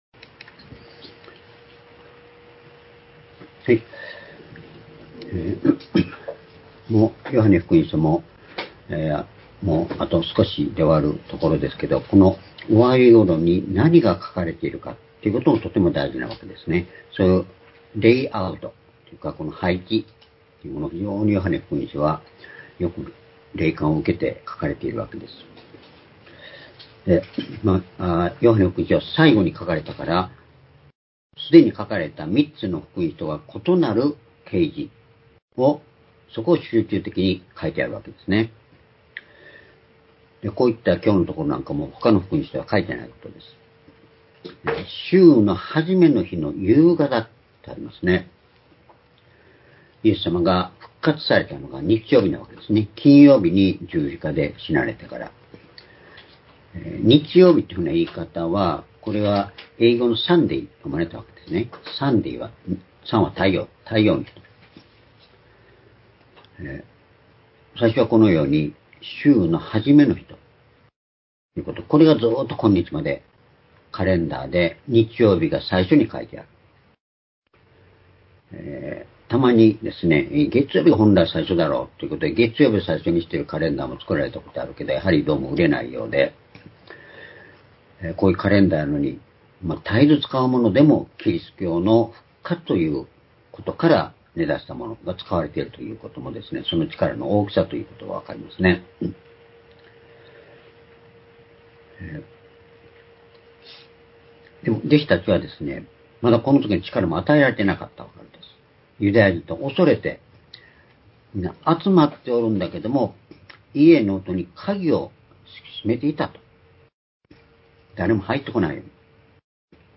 「鍵のかかった部屋に入ってくるイエス」―ヨハネ２０の１９－２０２５年４月６日（主日礼拝）